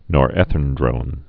(nôr-ĕthĭn-drōn)